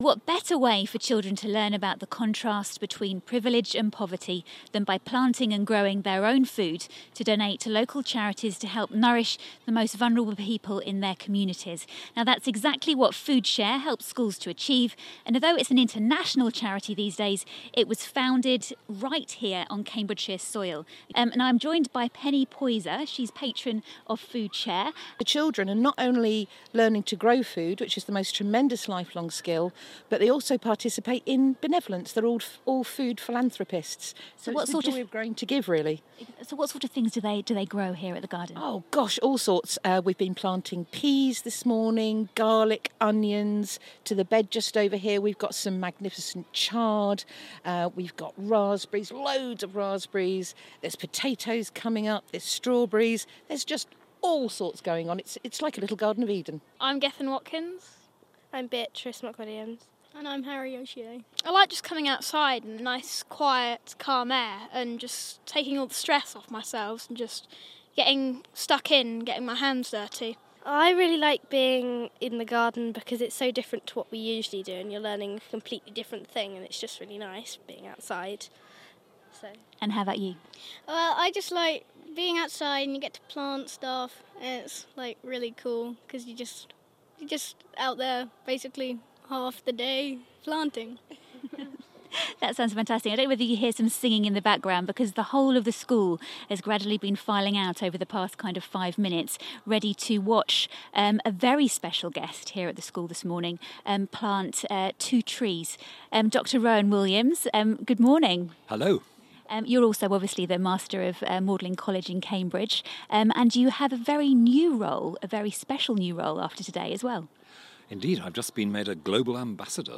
joined former Archbishop Rowan Williams on a visit to the FoodShare garden, at Milton Primary School, where pupils grow fruit and veg, to give to vulnerable people in the local community.